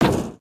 main Divergent / mods / Footsies / gamedata / sounds / material / human / step / metall04gr.ogg 5.9 KiB (Stored with Git LFS) Raw Permalink History
metall04gr.ogg